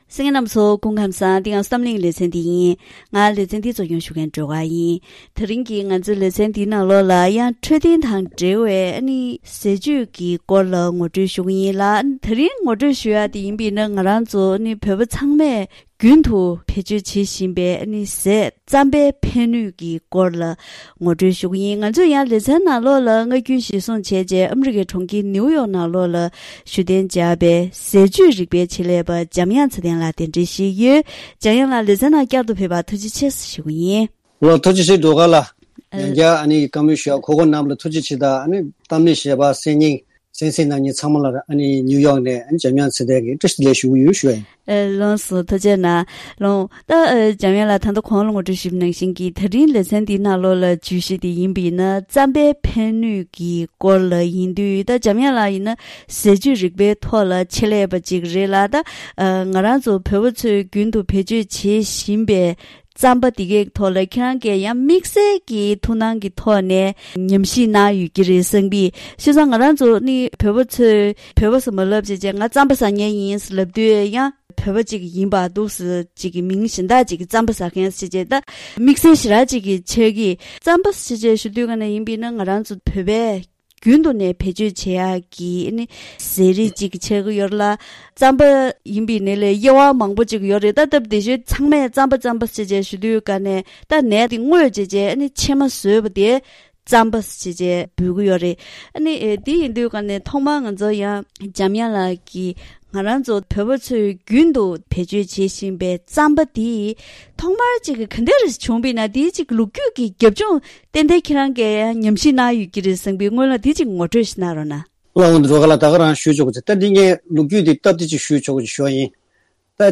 ད་རིང་གི་གཏམ་གླེང་ཞལ་པར་ལེ་ཚན་ནང་བོད་པས་ལོ་ངོ་སྟོང་ཕྲག་རིང་བེད་སྤྱོད་བྱེད་བཞིན་པའི་ནས་རྩམ་གྱིས་གཟུགས་པོའི་འཕྲོད་བསྟེན་ལ་ཕན་ཐོགས་ཡོད་པ་སྟེ། སྙིང་ཁམས་ཁྲག་རྩའི་ནད་དང་། འབྲས་ནད་རིགས། གཅིན་པ་མངར་འགྱུར་གྱི་ནད་སོགས་ལ་སྔོན་འགོག་གི་ནུས་པ་ལྡན་པའི་ཐོག་འཚོ་བཅུད་ཀྱི་རིགས་ཁག་ཅིག་ཡོད་པས། རྩམ་པའི་གྲུབ་ཆ་དང་ཕན་ནུས་སོགས་ཀྱི་སྐོར་ངོ་སྤྲོད་ཞུས་པ་ཞིག་གསན་རོགས་གནང་།